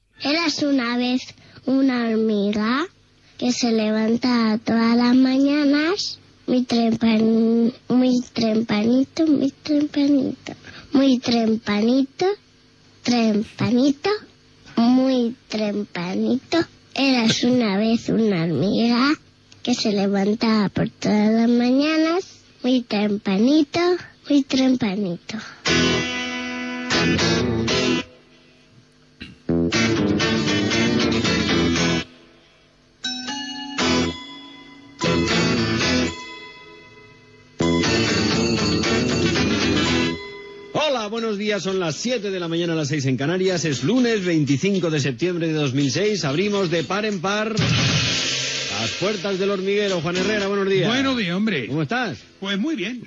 Careta del programa, feta per un nen, hora i inici del programa.
Entreteniment